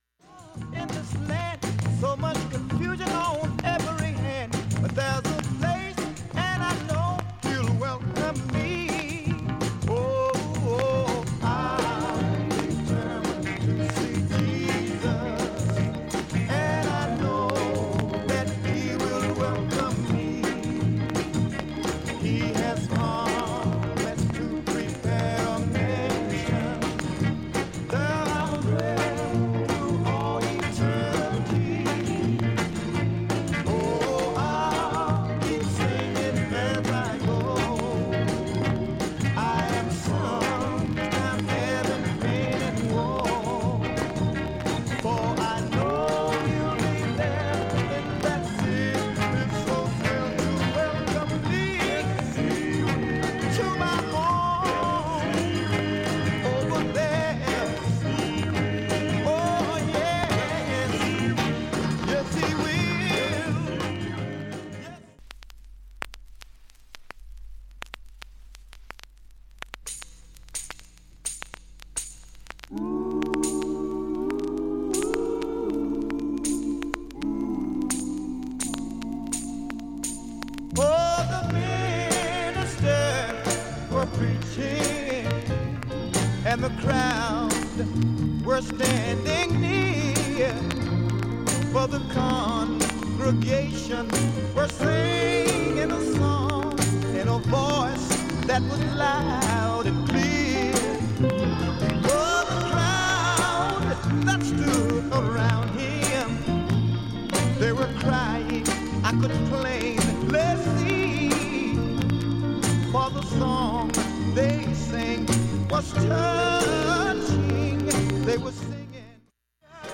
かなりかすかなレベルです。、
プツ自体はストレスはありません。
2,(1m06s〜)B-2に軽いチリプツ出ます。
3,(2m00s〜)B-3後半に１８回プツ出ます。
５０秒の間に周回プツ出ますがかすかです。
現物の試聴（上記録音時間5m31s）できます。音質目安にどうぞ
◆ＵＳＡ盤オリジナル Mono